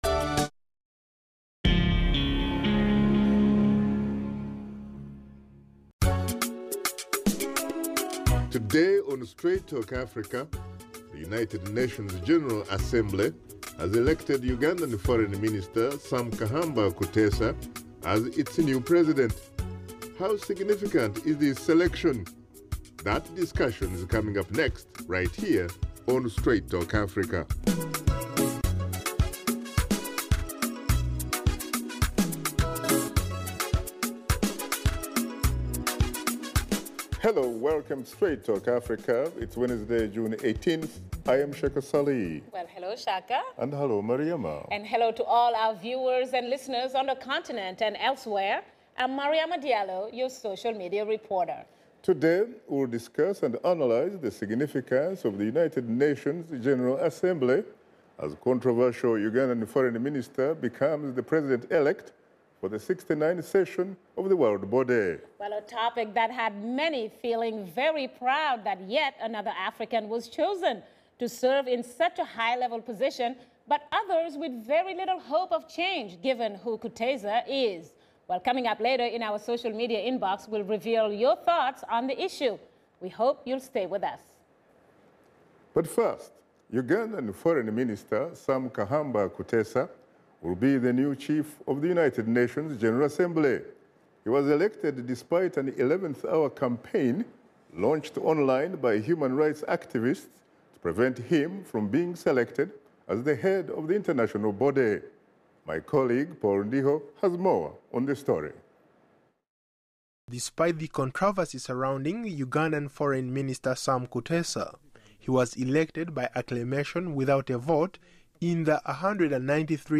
and his guests discuss the significance of the U.N. General Assembly as a controversial Ugandan Foreign Minister becomes the President-Elect of 69th session of the world body.